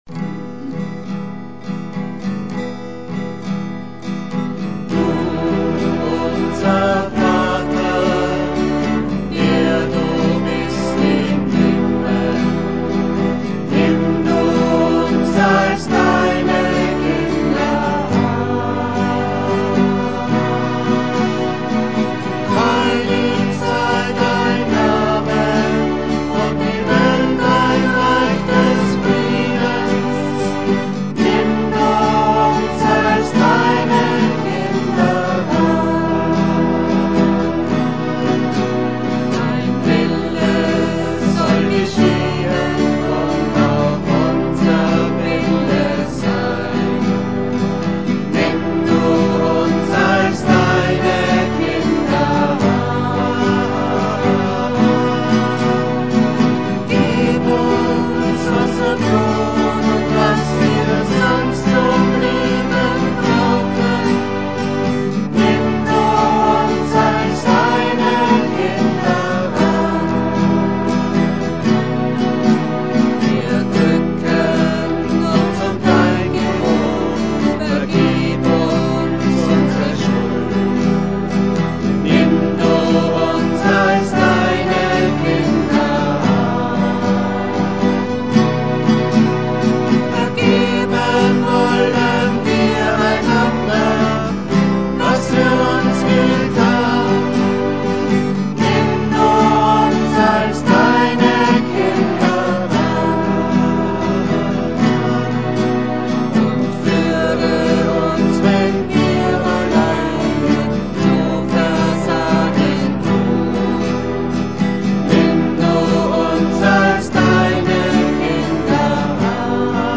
Gesang
Gesang, Gitarre
E-Piano, Keyboard